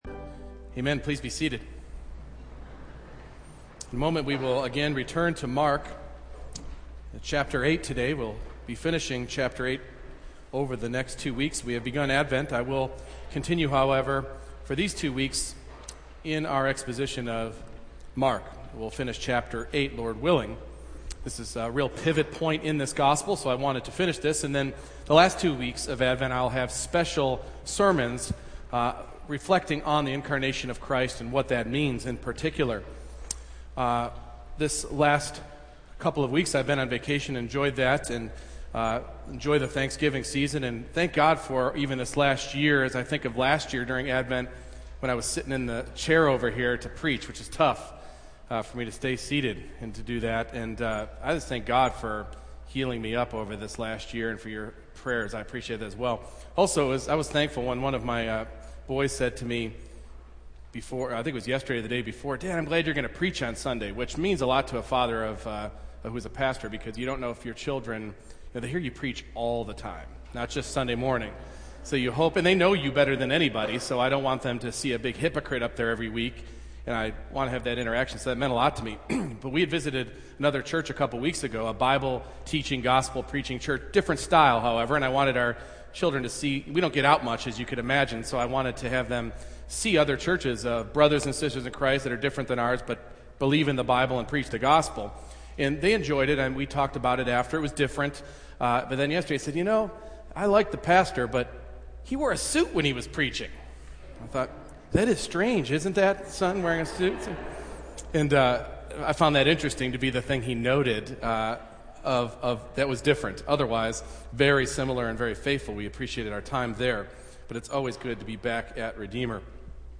Mark 8:27-33 Service Type: Morning Worship The pivotal question in this life- Who is Jesus Christ to you?